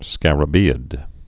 (skărə-bēĭd)